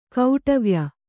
♪ kauṭavya